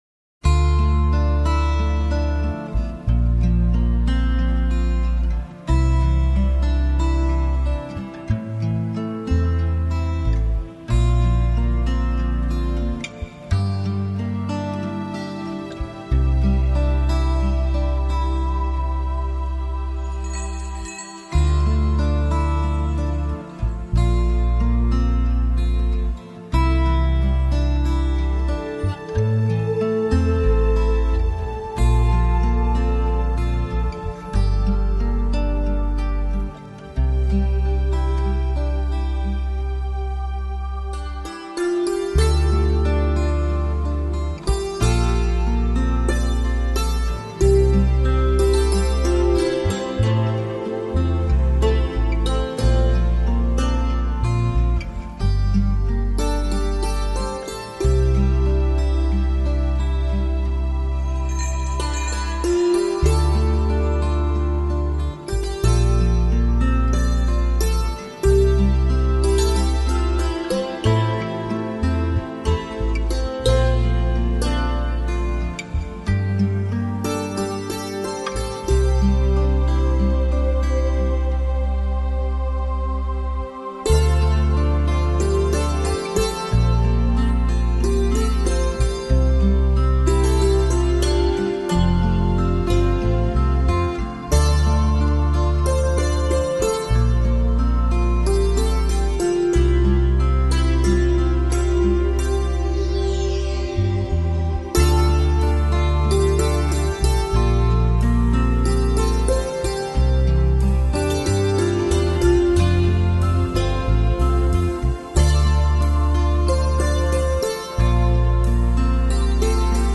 新世纪音乐发烧极品十六首平衡灵性音乐
这张CD收录的16首NewAge音乐，不单是一些旋律悦耳优美，使人心境开朗的音乐。
吉它弦上空灵的声音，一切恍如在梦中。